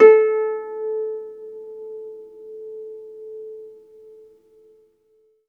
HARP BN4 SUS.wav